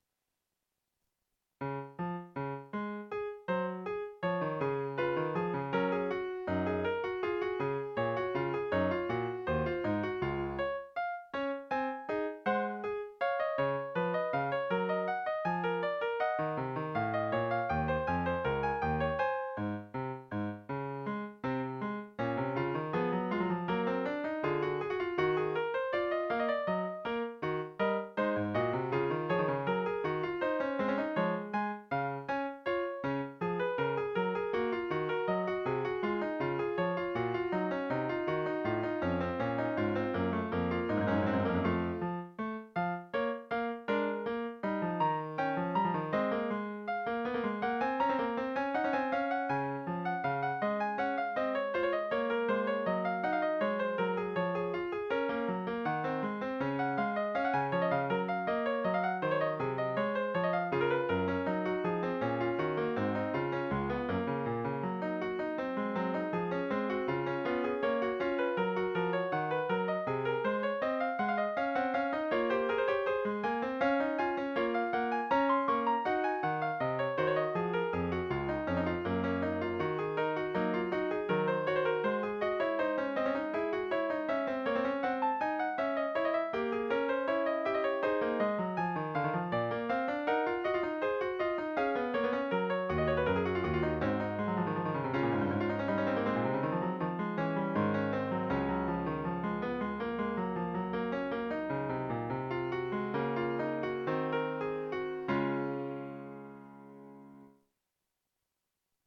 Werckmeister-III er en typisk barokstemning. Den lyder bedre end den ligesvævende omkring C og dårligere omkring Cis, men kan bruges i alle tonearter.Nedenfor kan du høre båce hele satsen og temaet og høre de "fejl" der fremgår af grafen for de første tre takter.